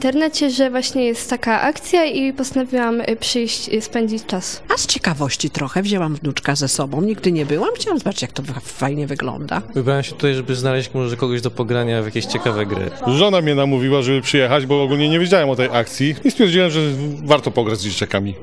Zapytaliśmy uczestników, dlaczego wybrali się na Noc Bibliotek oraz czy im się podobało.